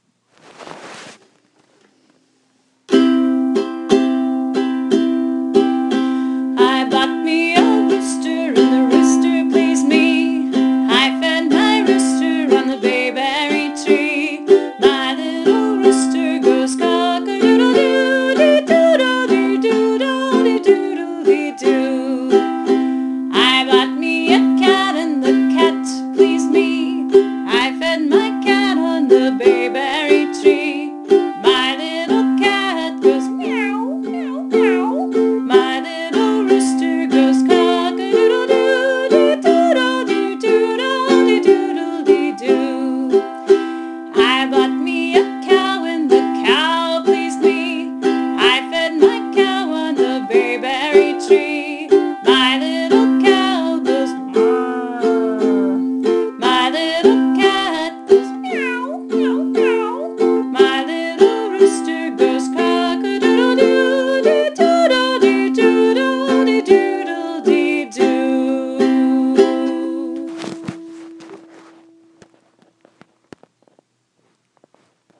Posted in Rhymes and songs, Storytime themes, Ukulele by
To go along with The Artist Who Painted a Blue Horse, we sang this song, and I asked the kids to supply silly animal and color combinations: purple dog, rainbow horse, etc.  Here are the words with the ukulele chords (click on the triangle for the tune):